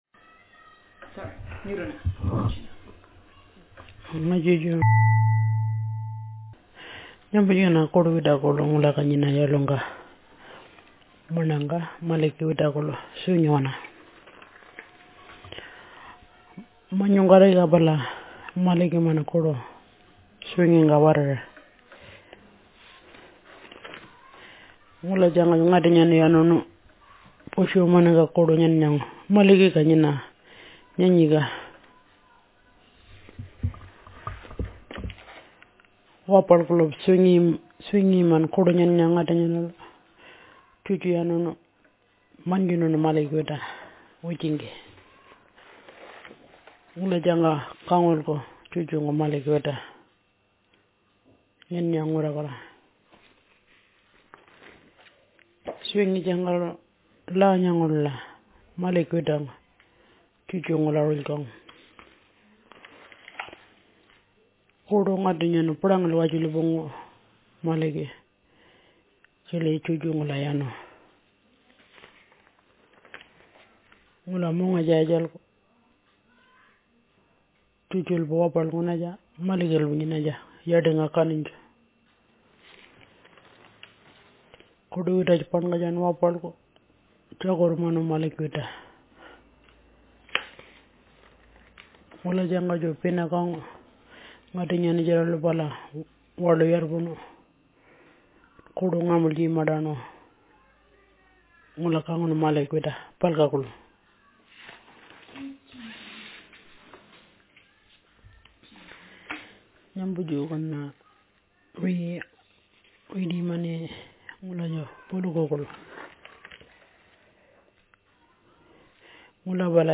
Speaker sex f Text genre stimulus retelling